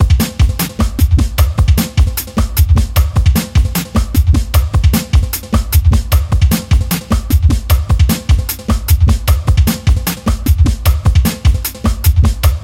free loops/beats/sequences/patterns/synthesizersounds/percussionloops/thingers " simple 120bpm drum loop
描述：使用我制作的样品，在flstudio中简单的120bpm鼓环mkade
标签： RH演奏节奏 120BPM 简单 打击乐循环 量化 鼓环
声道立体声